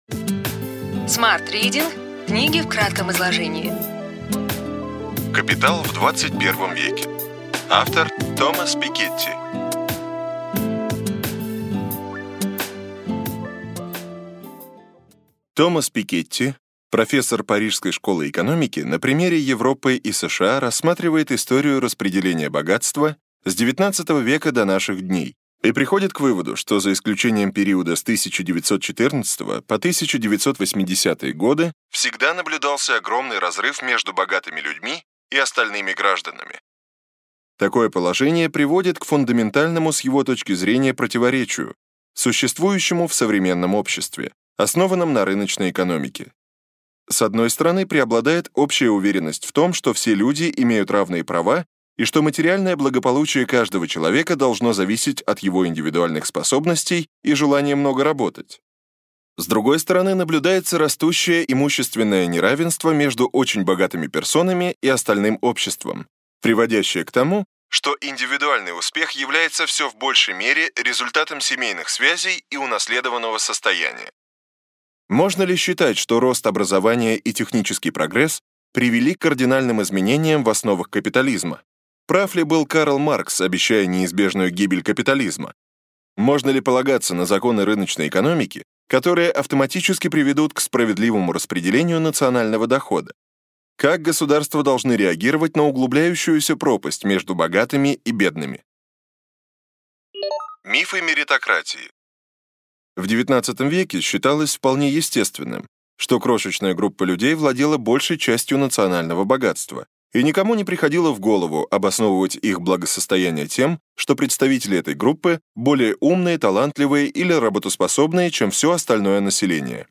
Аудиокнига Ключевые идеи книги: Капитал в двадцать первом веке. Томас Пикетти | Библиотека аудиокниг